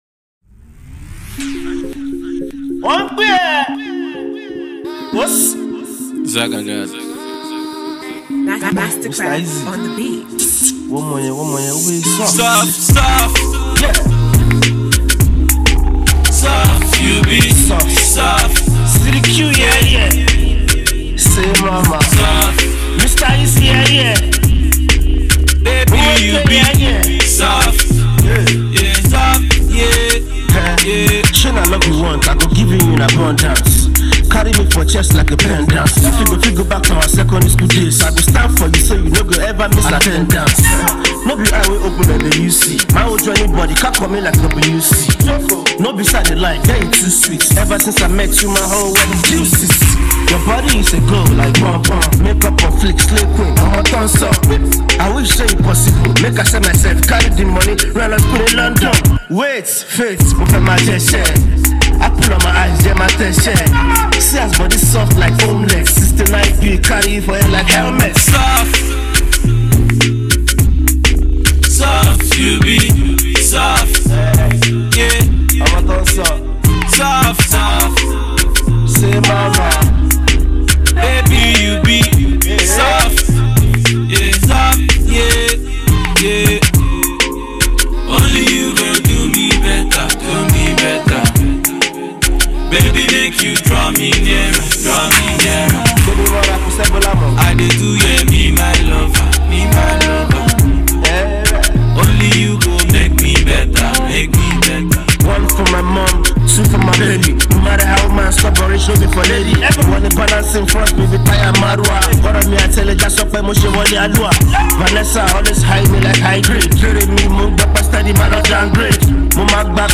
indigeneous rap
mid-tempo song